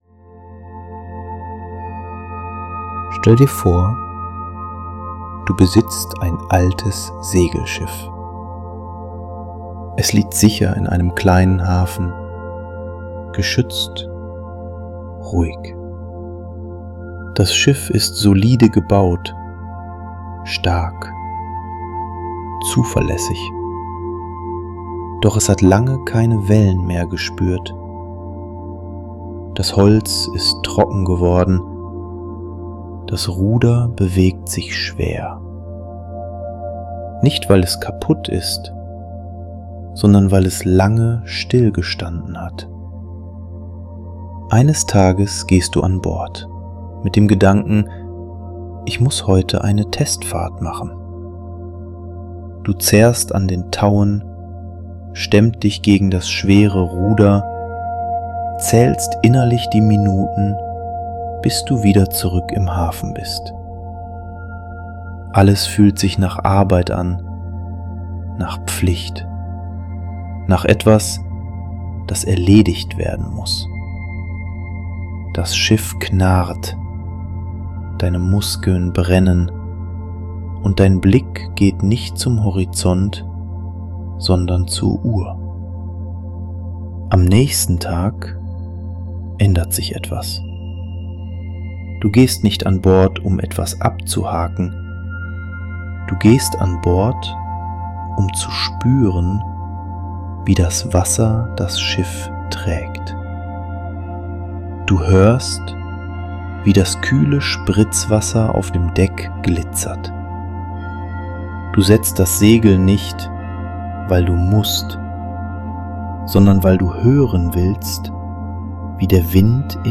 • Therapeutisch aufgebautes Hypnose-Programm
• Klare, ruhige Sprache – ohne Druck oder Überwältigung